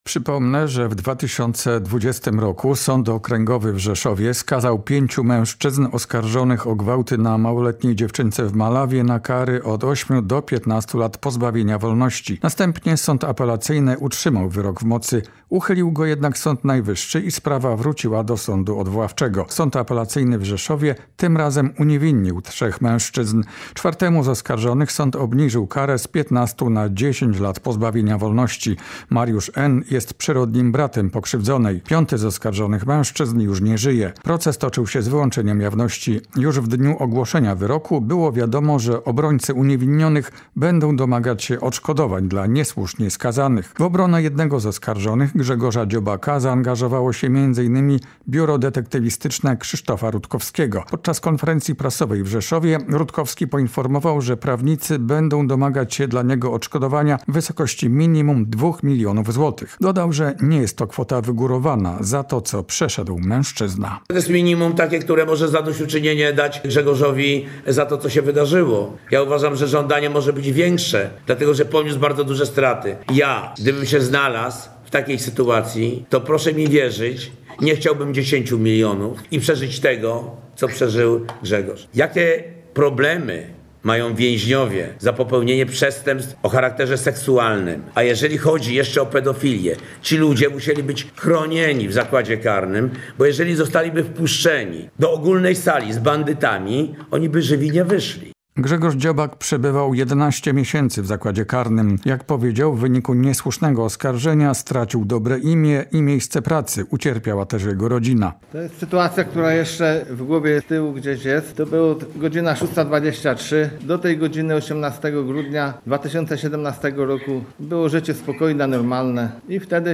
Poinformował o tym na konferencji prasowej w Rzeszowie Krzysztof Rutkowski z biura detektywistycznego.